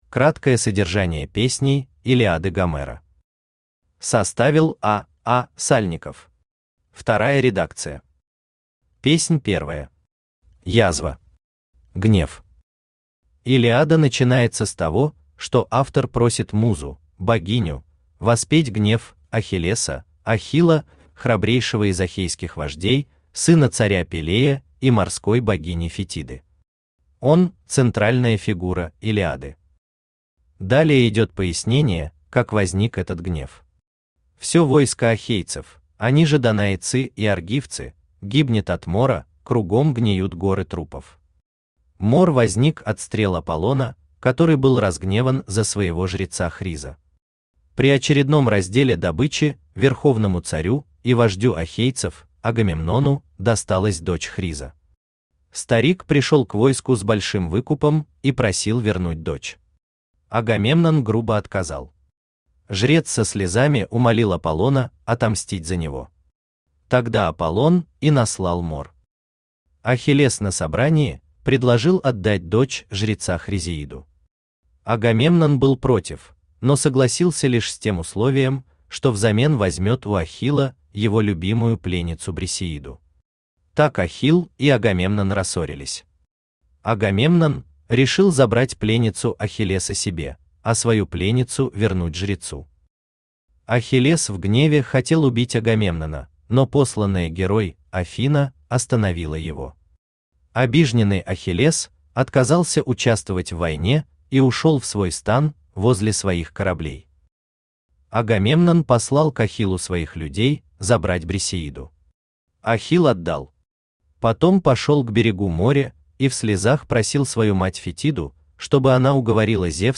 Аудиокнига Краткое содержание «Илиады» Гомера | Библиотека аудиокниг
Читает аудиокнигу Авточтец ЛитРес.